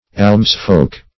Search Result for " almsfolk" : The Collaborative International Dictionary of English v.0.48: Almsfolk \Alms"folk`\ ([add]mz"f[=o]k`), n. Persons supported by alms; almsmen.